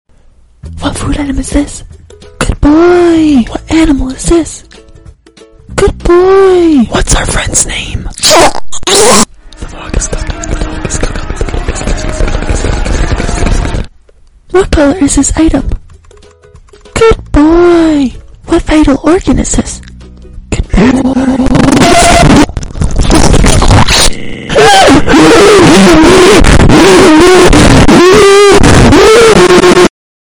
Astro bot asmr sound effects free download